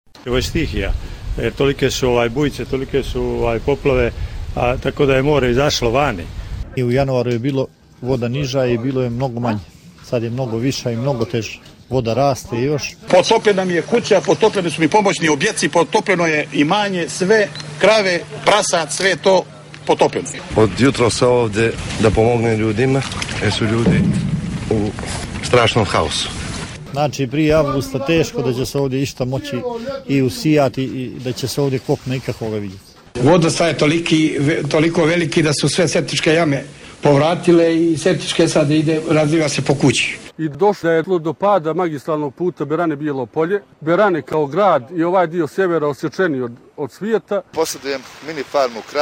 Anketa: Stanovnici poplavljenih područja u Crnoj Gori